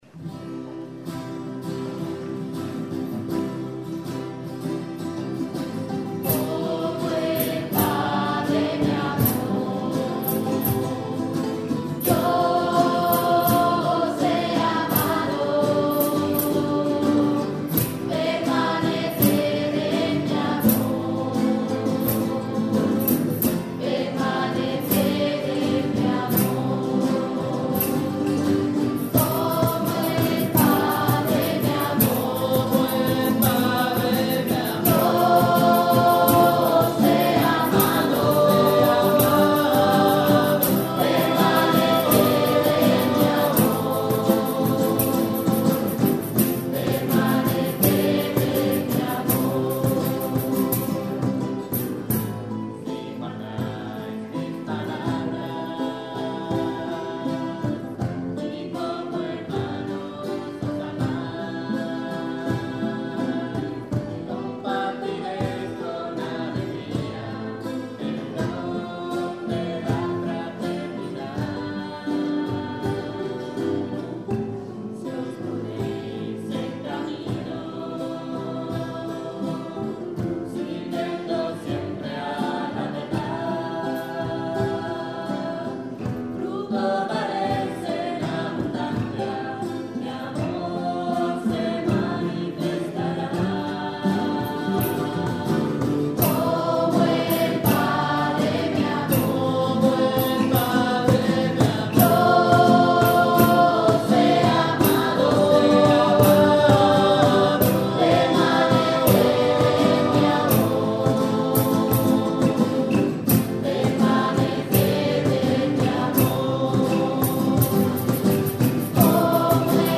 Canto: